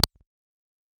Retro_8-Bit_Game-Interface_UI_20.wav